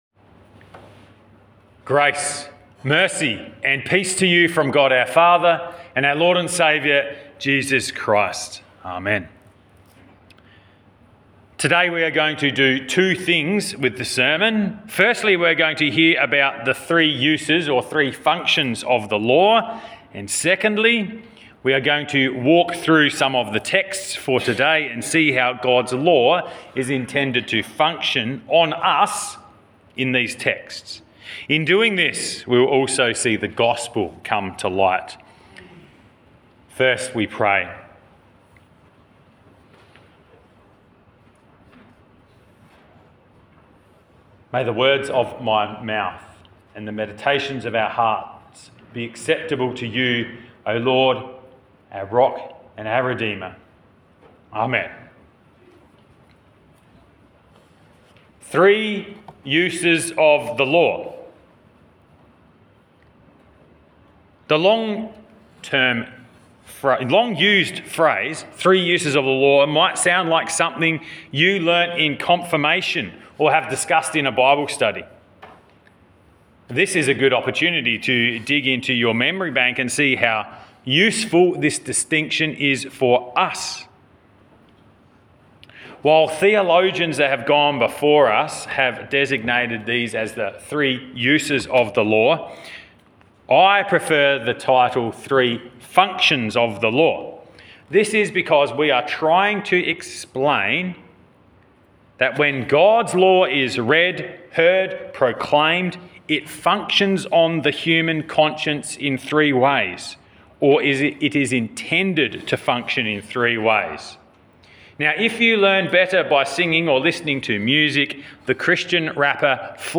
Sermon for September 10